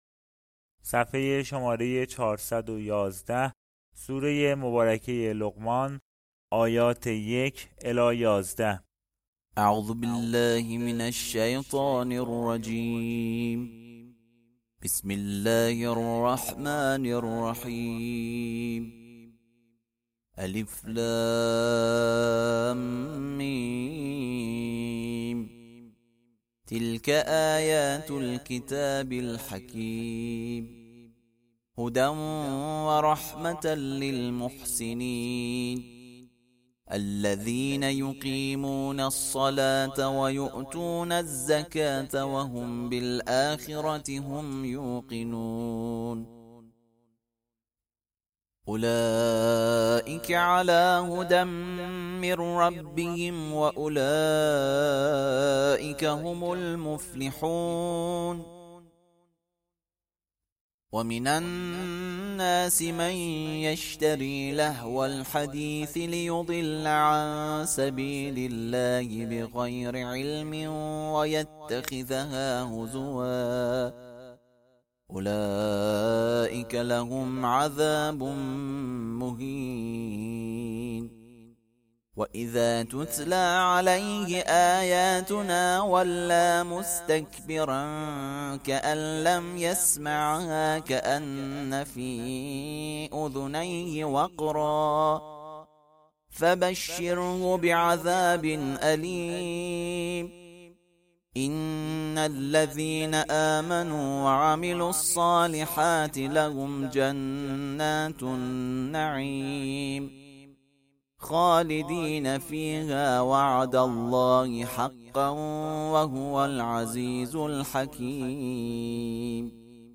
ترتیل سوره (لقمان)